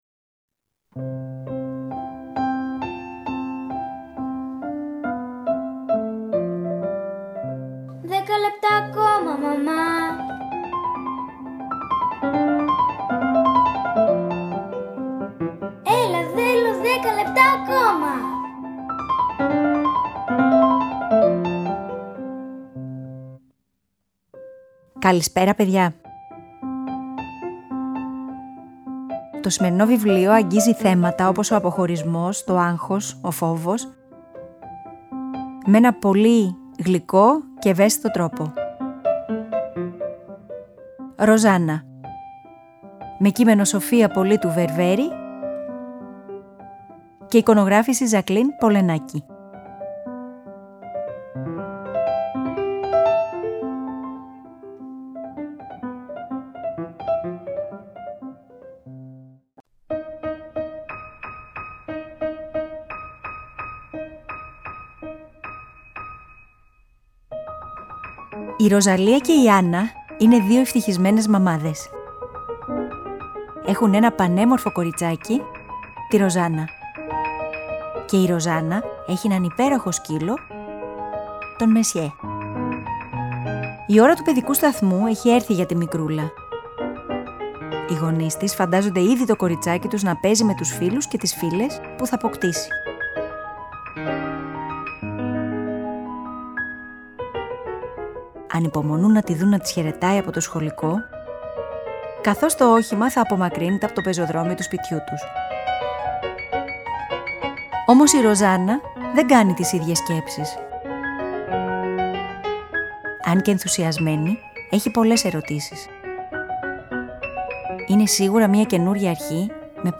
Αφήγηση-Μουσικές επιλογές: